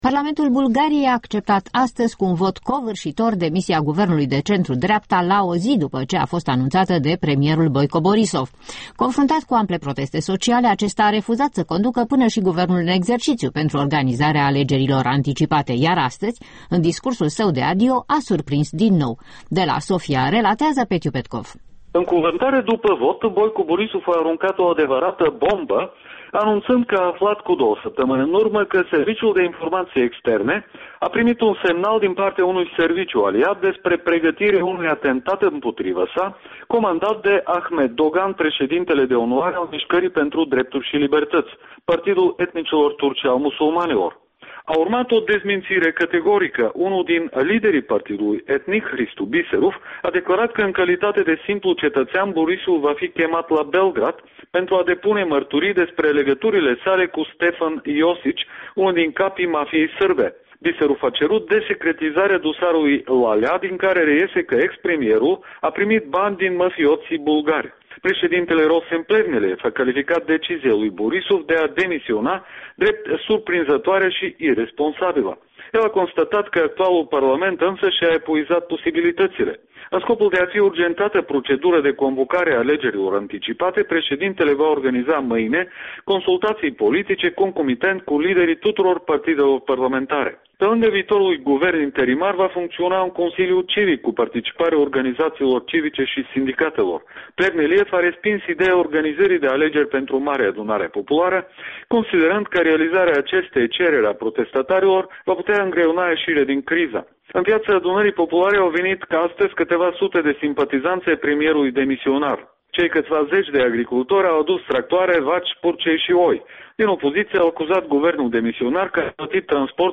In direct de la Sofia despre criza politică din Bulgaria